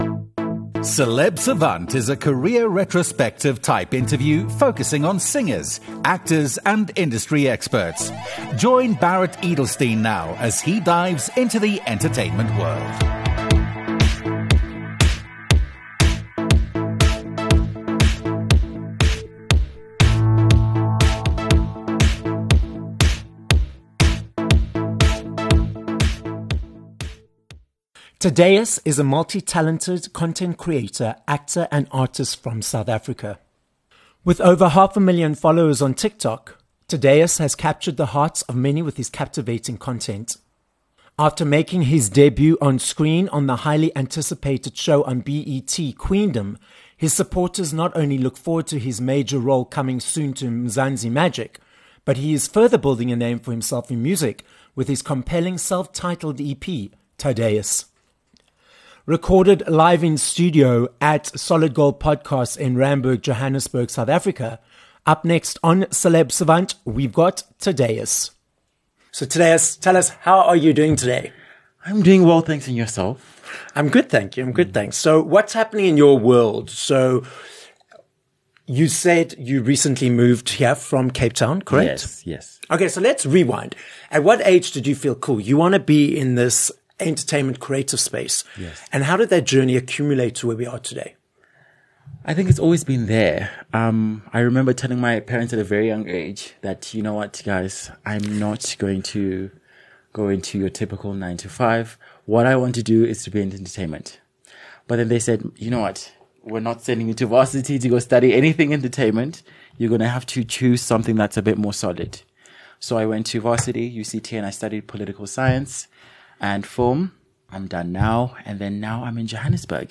This episode was recorded live in studio at Solid Gold Podcasts, Randburg, Johannesburg, South Africa TikTok